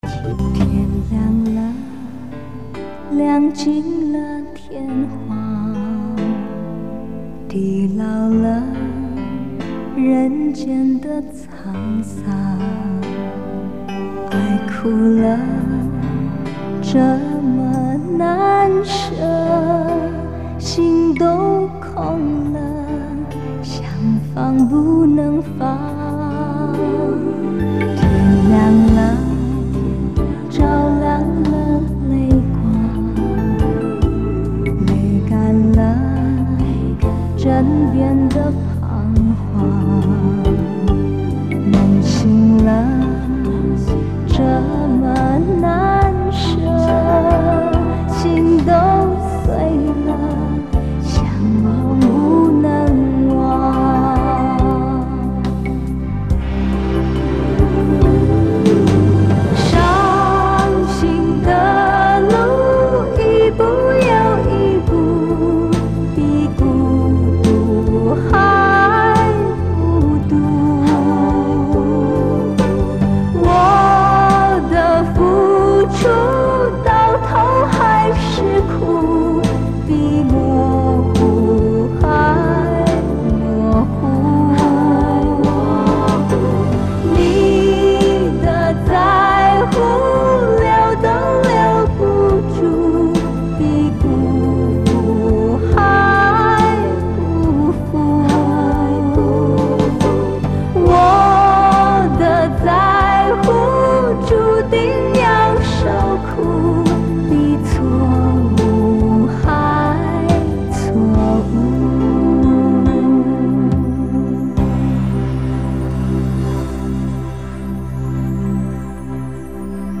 [10/12/2008]我们来讨论一下“古典味道浓、略微带些伤情、词美曲美意境美”的华语歌曲罢
片尾曲